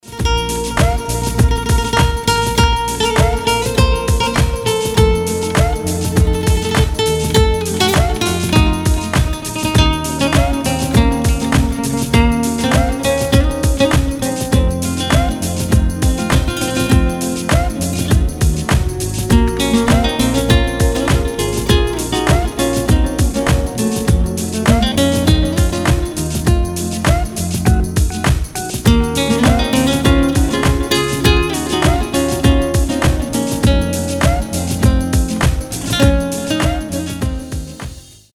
• Качество: 320, Stereo
гитара
без слов
красивая мелодия
медленные
New Age
Красивая мелодия с интересным ритмом